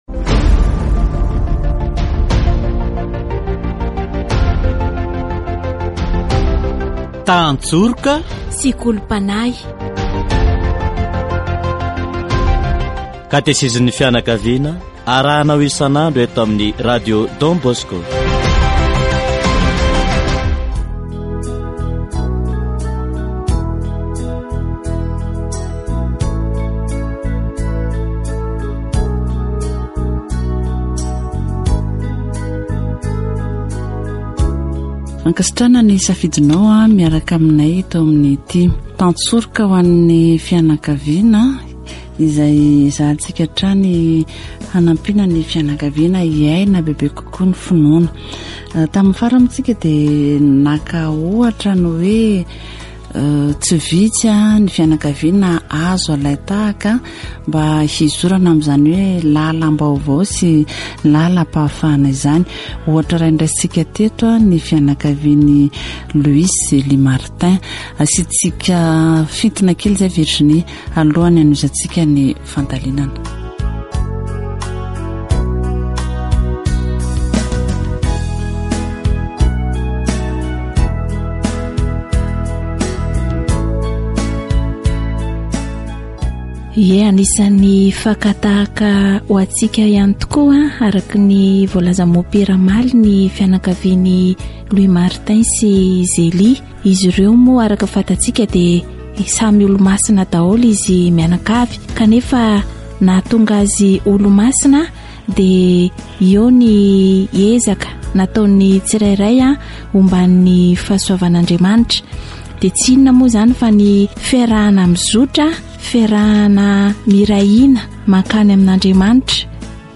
To walk the new path required by the Gospel, we must try, as individuals and as families, to experience the poverty that the Gospel teaches. This poverty is mainly based on the fact that it does not depend on the things of the earth, but on a total trust in God. Catechesis on the way to freedom